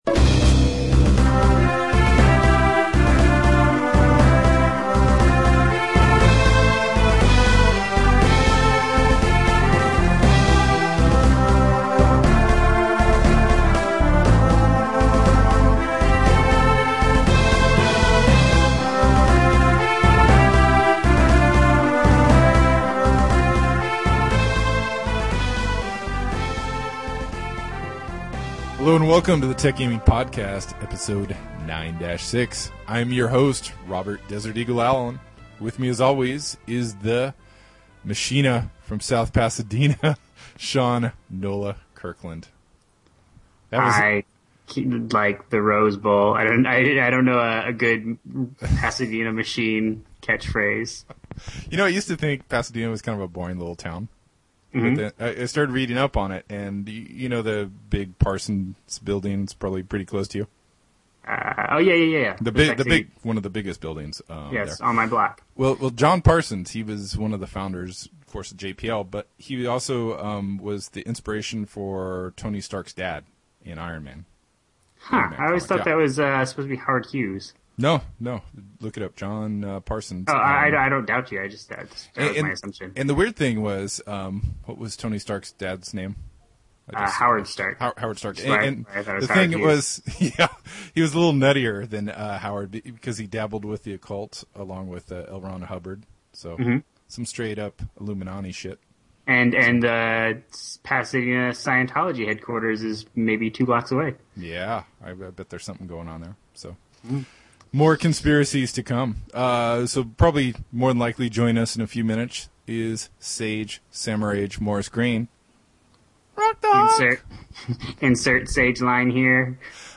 Stay tuned for our interview